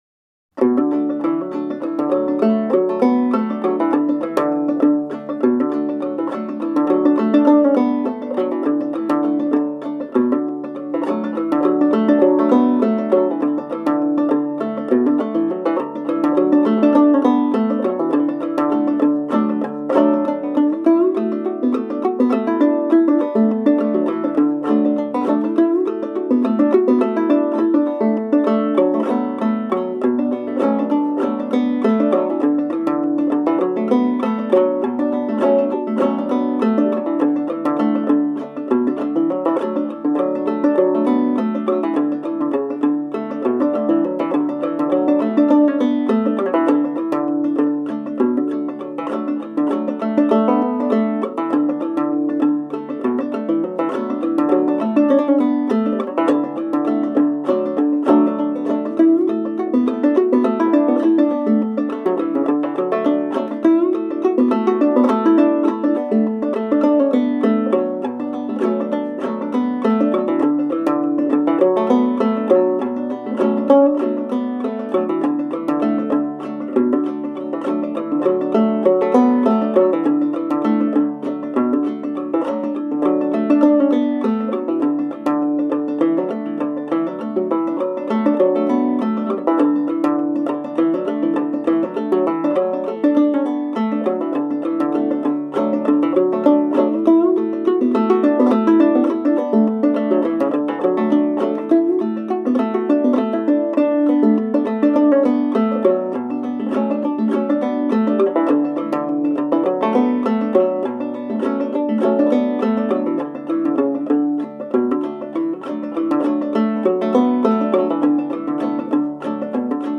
Been listening to a lot of bluegrass lately.